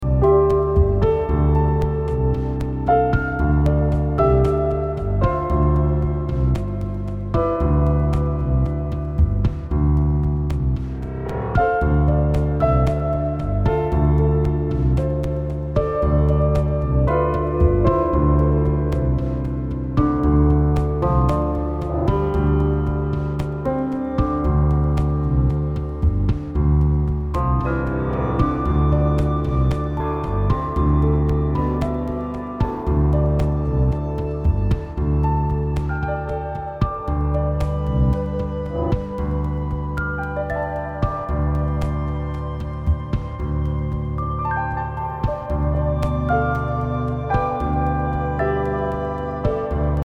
Experimental >
Electronica >
Post Classical >